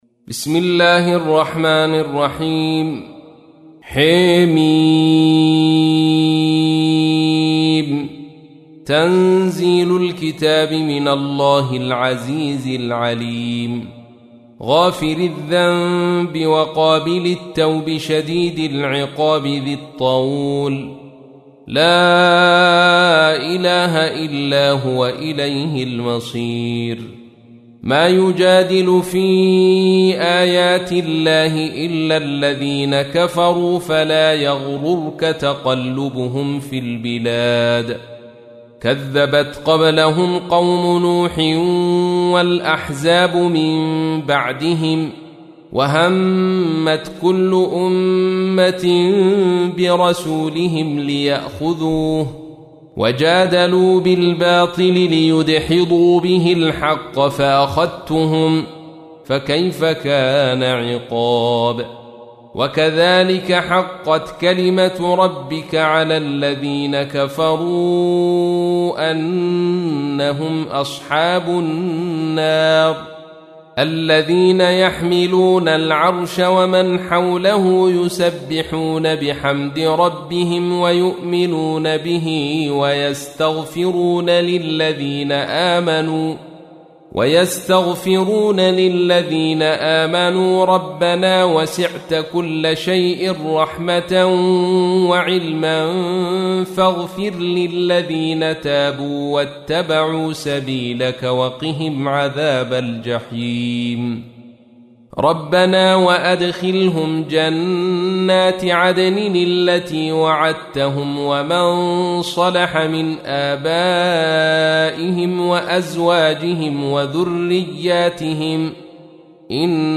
تحميل : 40. سورة غافر / القارئ عبد الرشيد صوفي / القرآن الكريم / موقع يا حسين